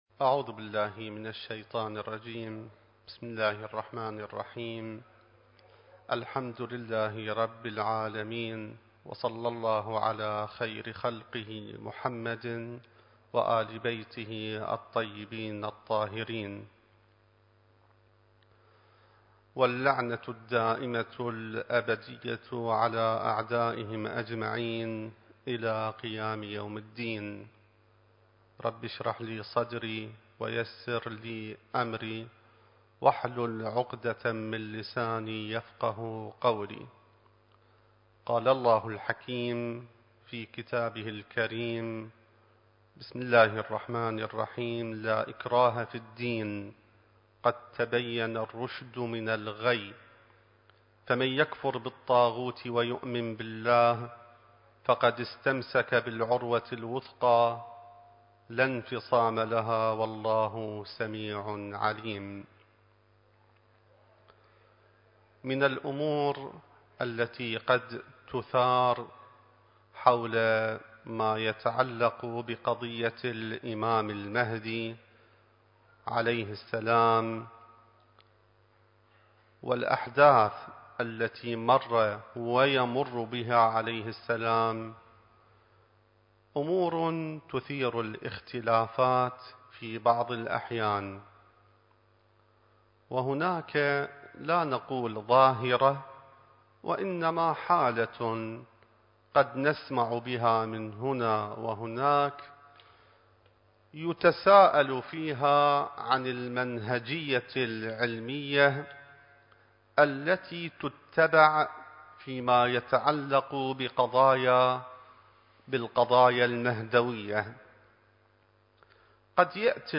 المكان: العتبة العلوية المقدسة الزمان: ذكرى ولادة الإمام المهدي (عجّل الله فرجه) التاريخ: 2021